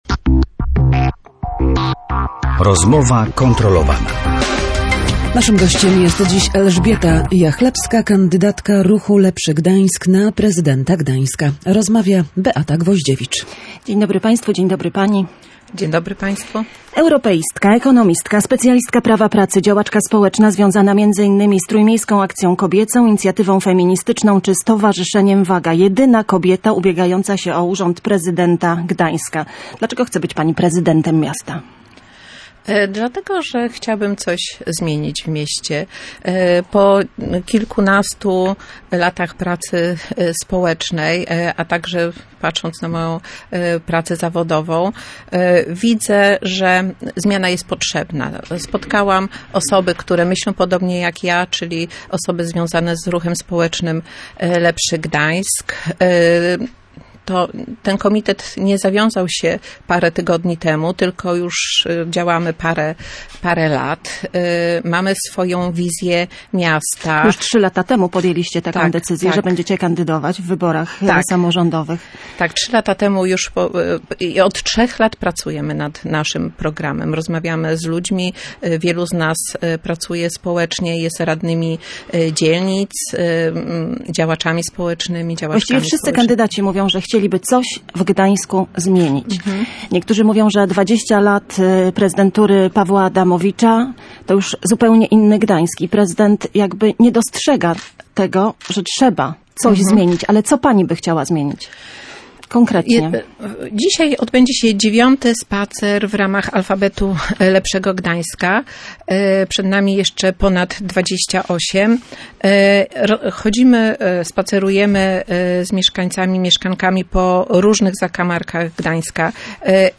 Posłuchaj audycji Gość Dnia Radia Gdańsk: